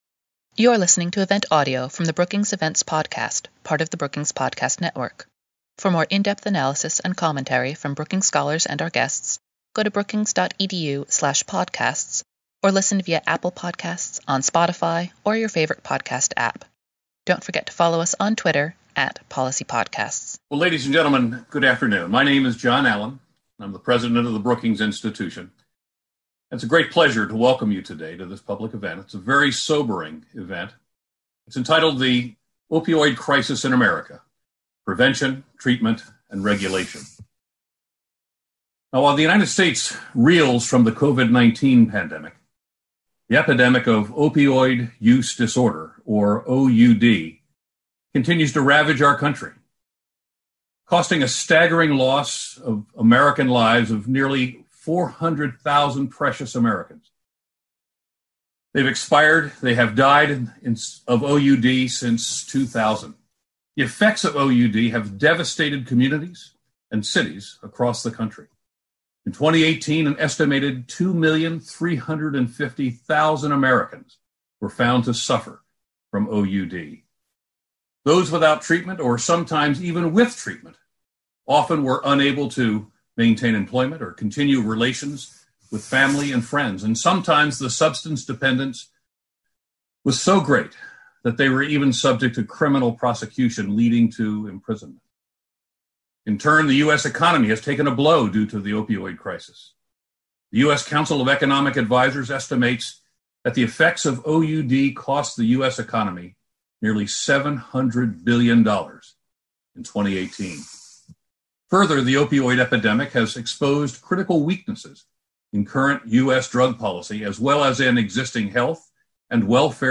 Webinar: The opioid crisis in America – Prevention, treatment, and regulation